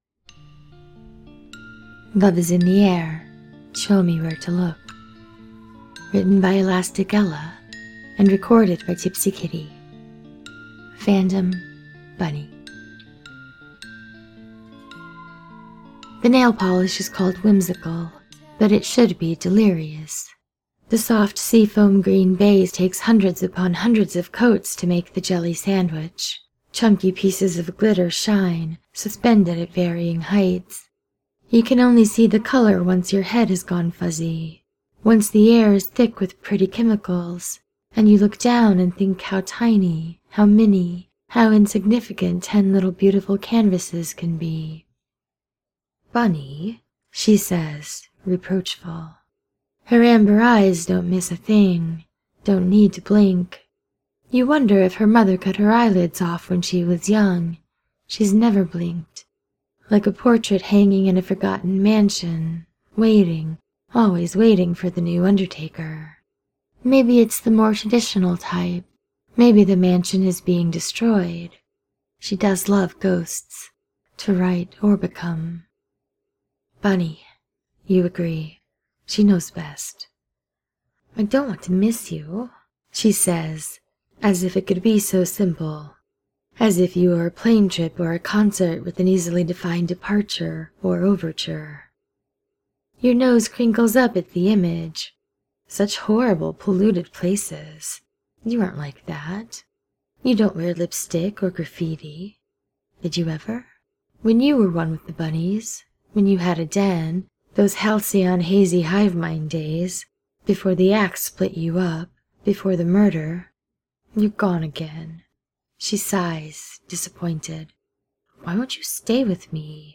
with music: